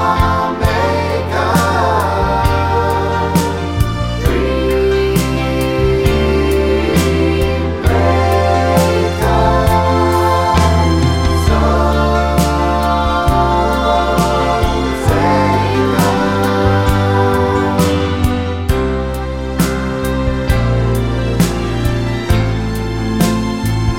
No Backing Vocals Duets 3:58 Buy £1.50